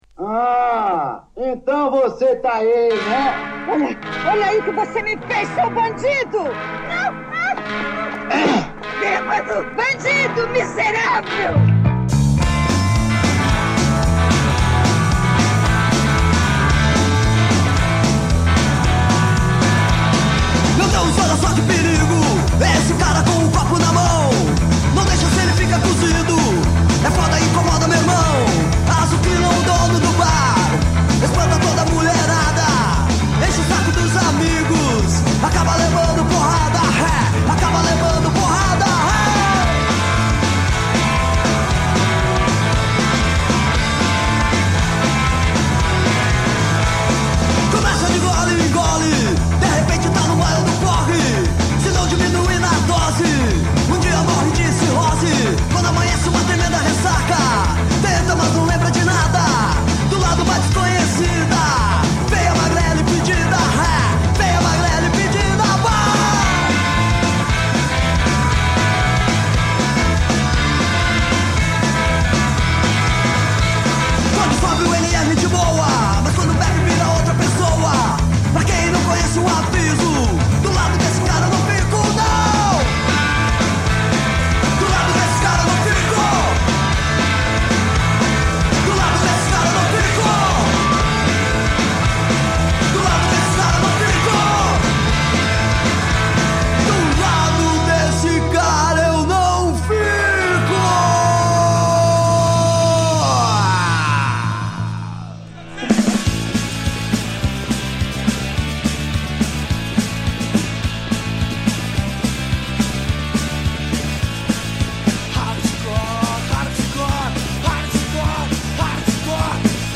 Mix Punk Rockabilly Psycobilly Post Punk Rock n Roll http